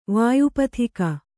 ♪ vāyu pathika